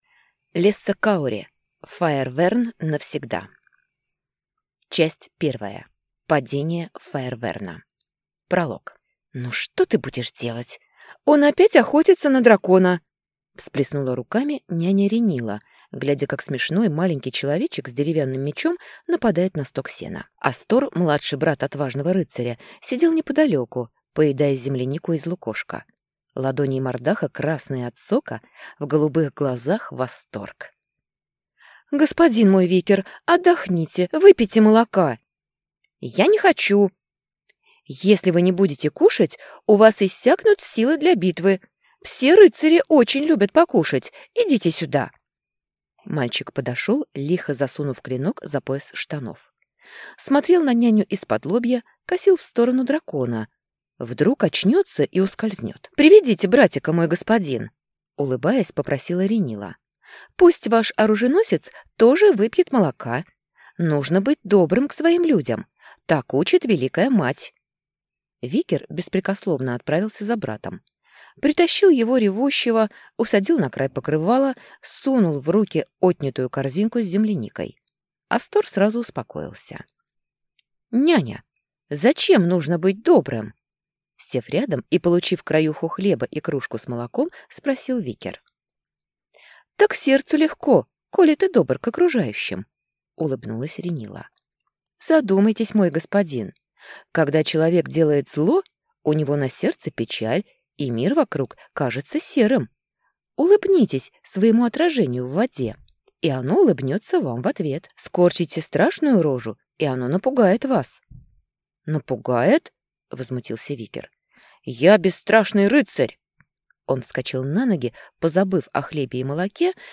Аудиокнига Фаэрверн навсегда | Библиотека аудиокниг